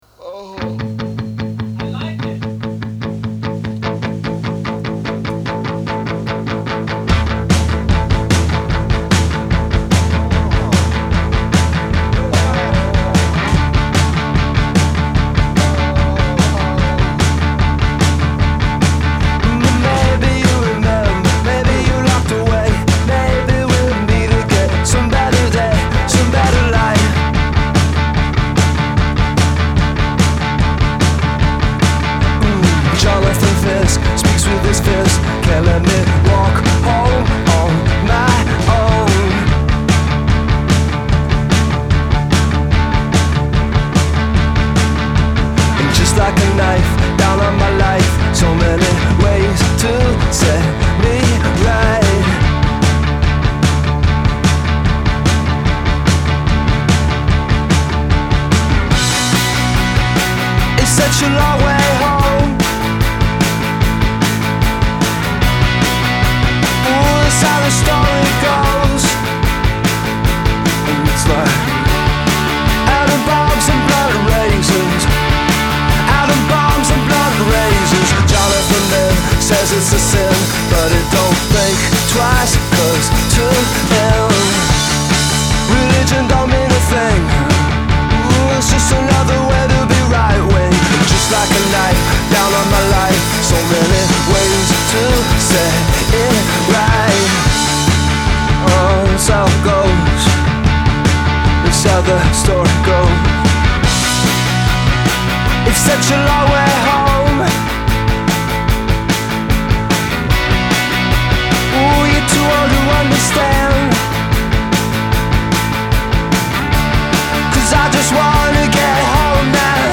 rocker